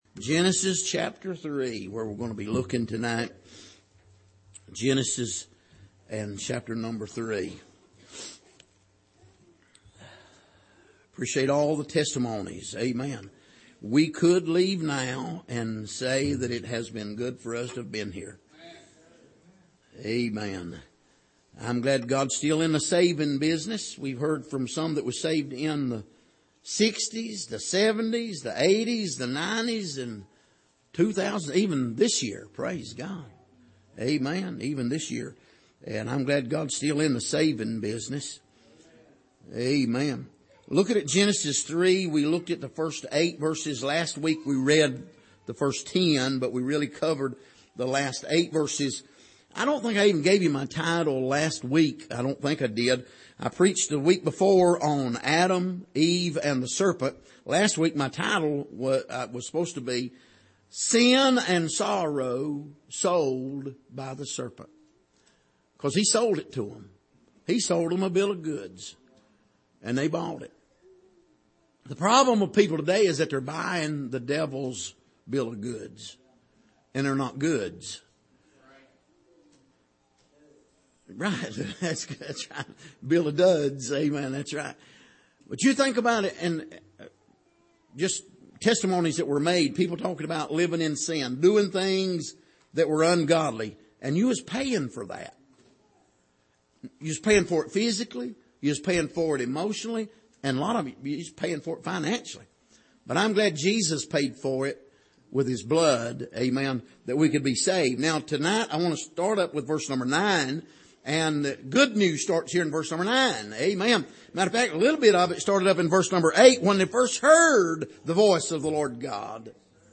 Passage: Genesis 3:9-15 Service: Sunday Evening